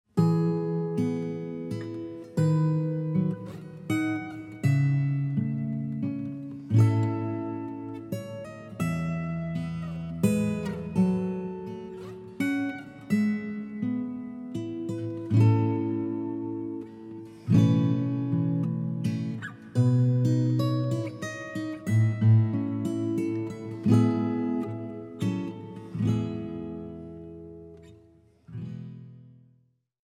彼らの目指すクラッシクスタイルの新しい解釈・アレンジ・演奏により、新たなる魅力が吹き込まれる。
シドニーフォックススタジオEQで録音され